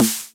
snare1.ogg